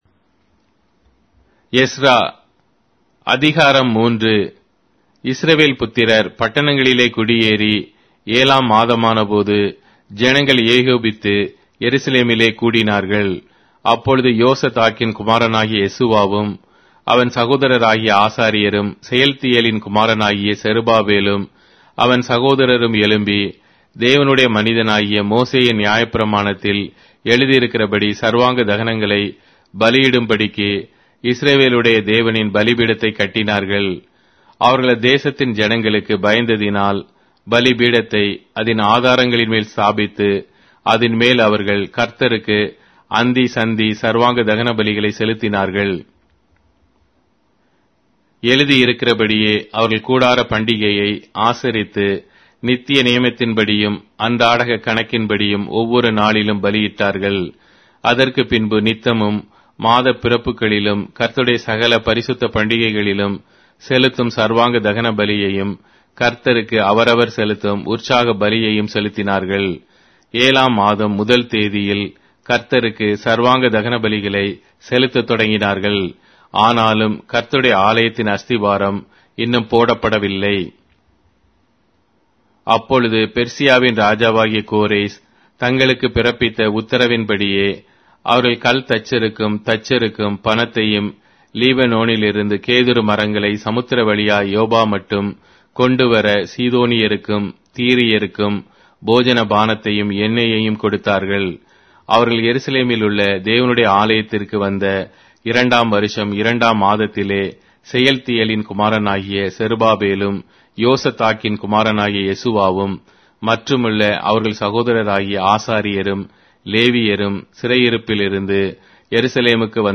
Tamil Audio Bible - Ezra 3 in Ervml bible version